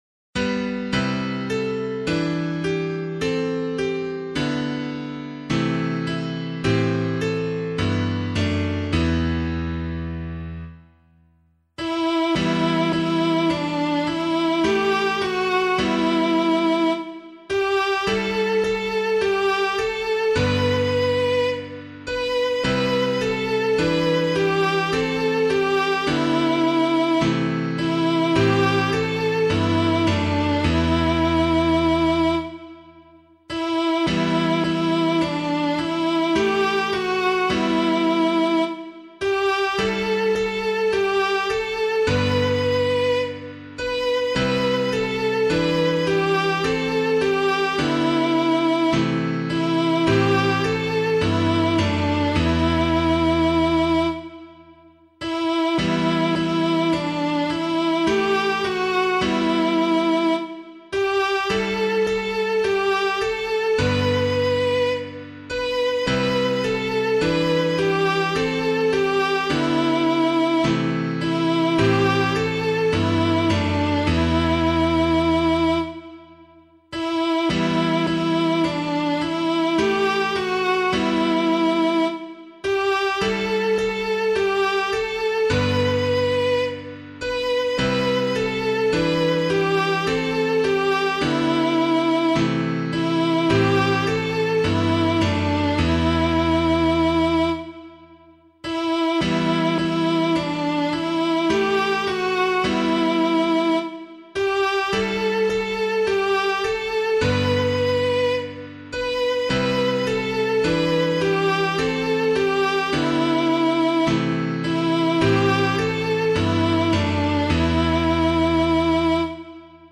Hymn of the Day:  The Holy Family of Jesus, Mary and Joseph
piano